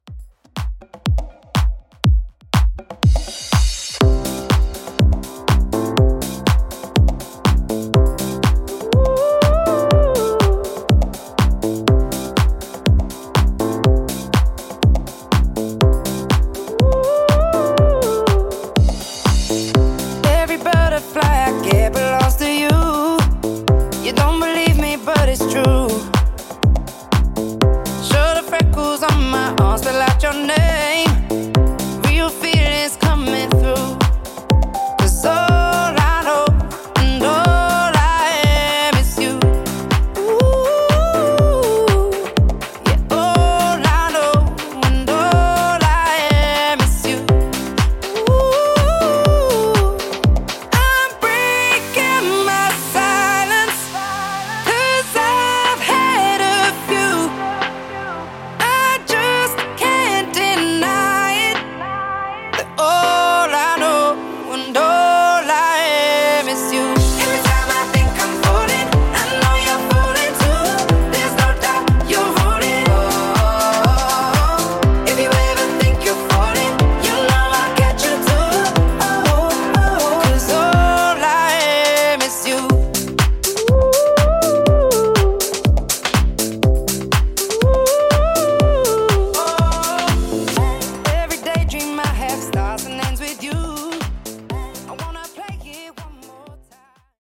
Club Redrum)Date Added